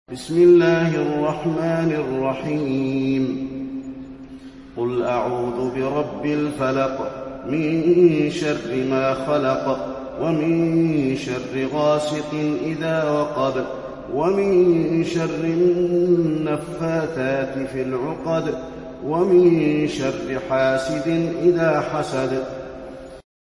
المكان: المسجد النبوي الفلق